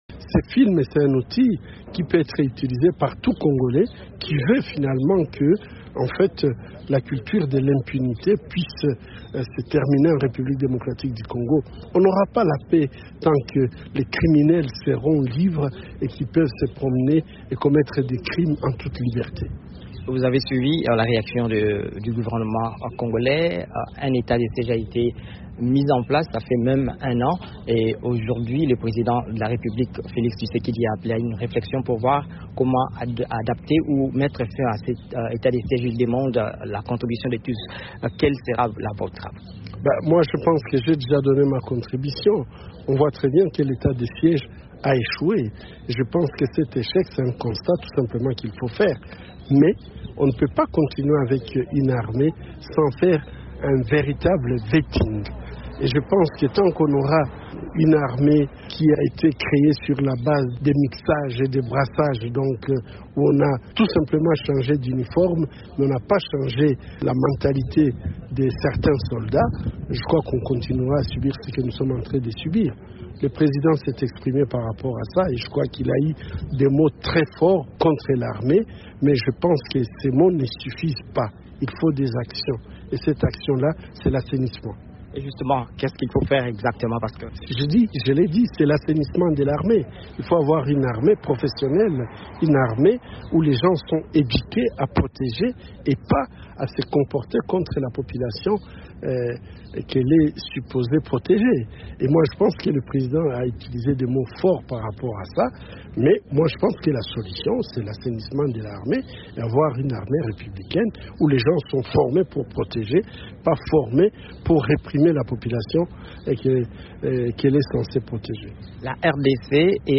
Ce film met en lumière les atrocités commises dans les conflits armés souvent oubliés dans l'est de la RDC, où des milliers de femmes ont été victimes de violences sexuelles. Un entretien